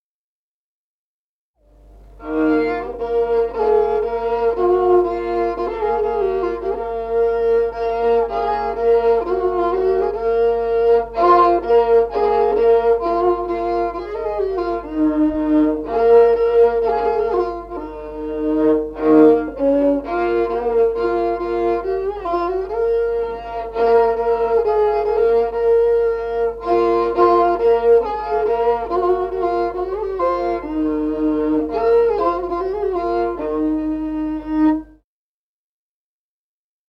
Музыкальный фольклор села Мишковка «Заручёна девчоночка», партия 2-й скрипки.